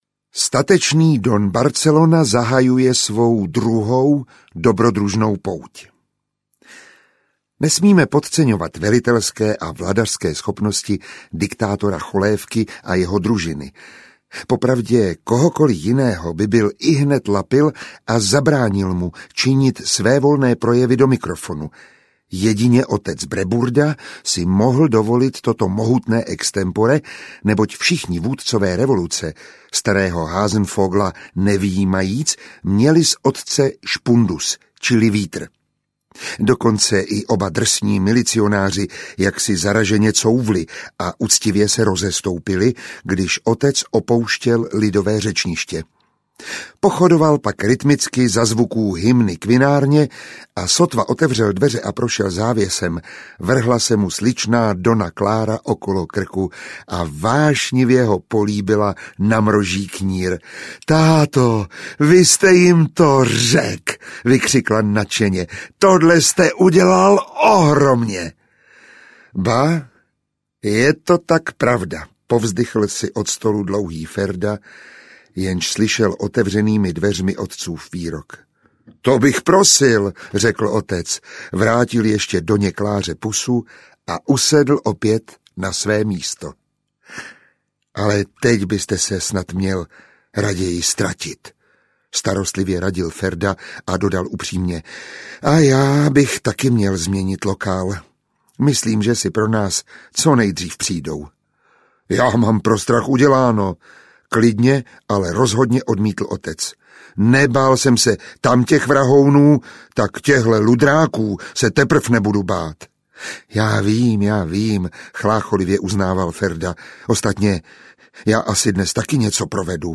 Konec starých časů audiokniha
Ukázka z knihy
• InterpretMiroslav Táborský